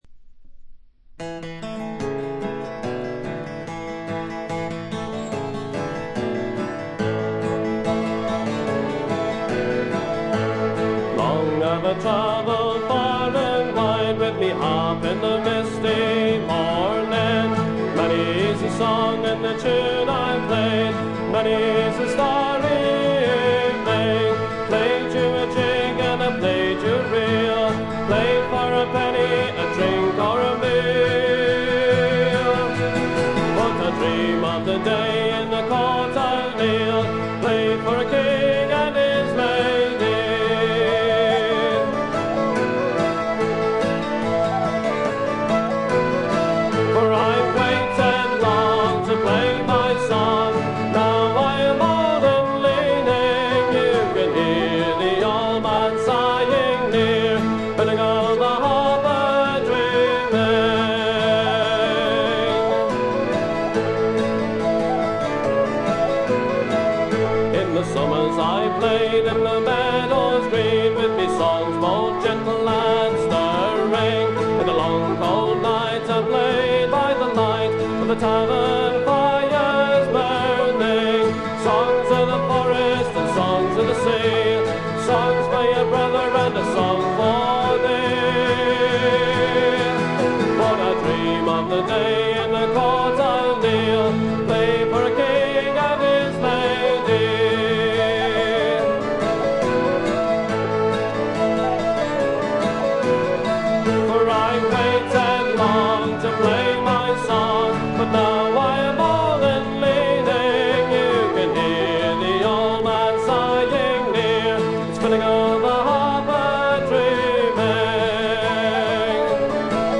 ごくわずかなノイズ感のみ。
アコースティック楽器のみで、純度の高い美しい演奏を聴かせてくれる名作です。
試聴曲は現品からの取り込み音源です。